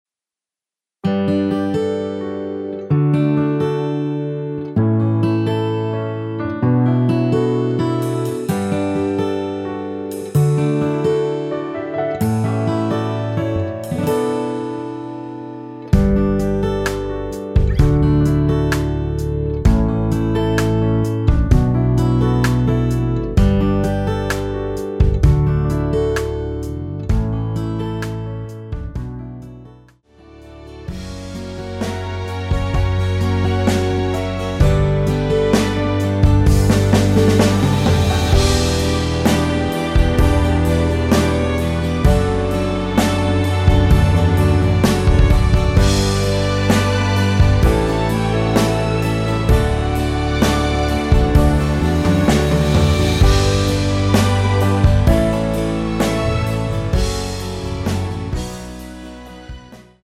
대부분의 남성분이 부르실수 있는키로 제작 하였습니다.
원키에서(-8)내린 MR입니다.
앞부분30초, 뒷부분30초씩 편집해서 올려 드리고 있습니다.